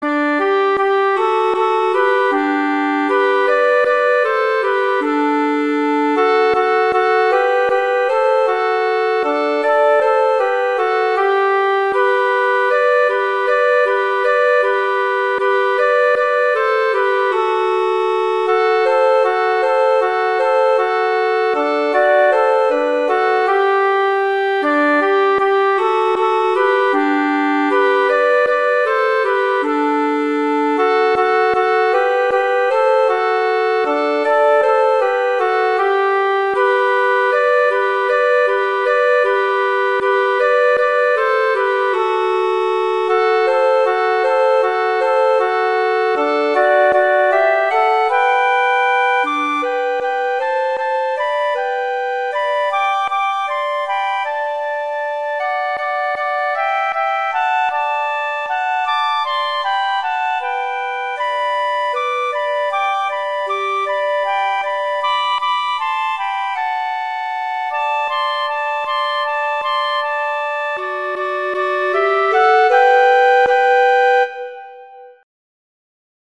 Instrumentation: flute & clarinet
arrangements for flute and clarinet